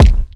Shady_Kick_1.wav